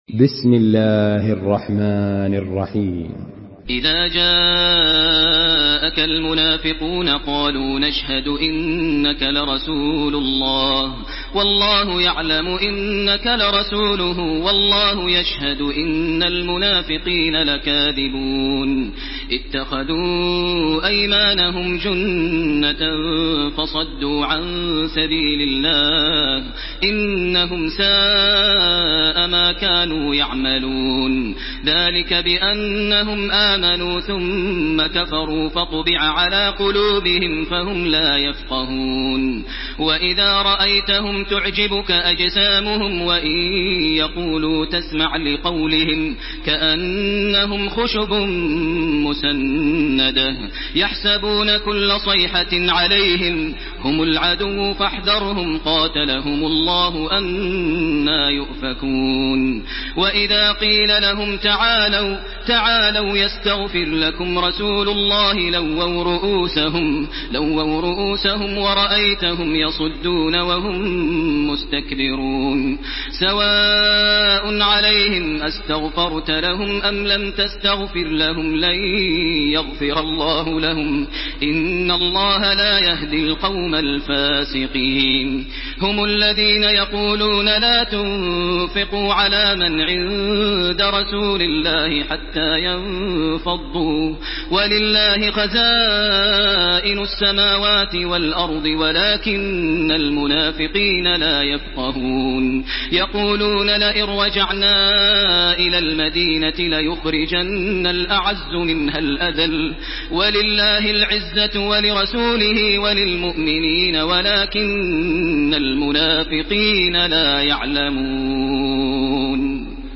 Listen and download the full recitation in MP3 format via direct and fast links in multiple qualities to your mobile phone.
تراويح الحرم المكي 1429
مرتل